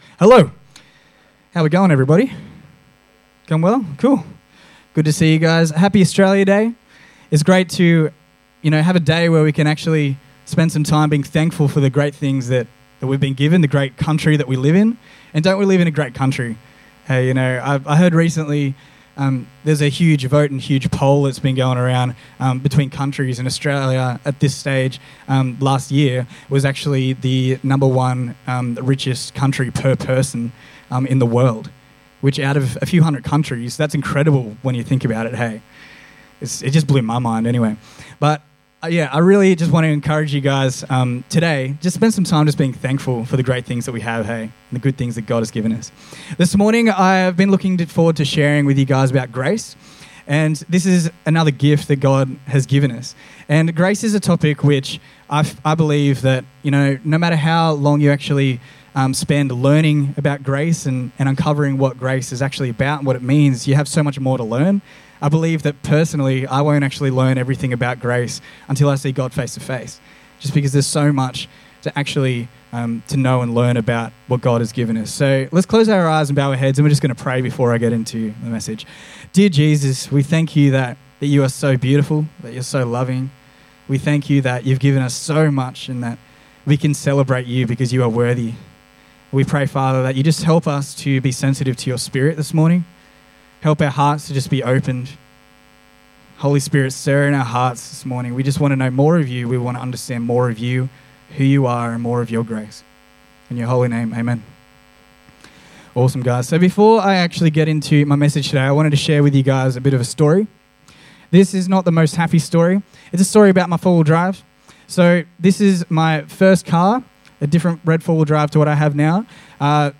preached at the morning service.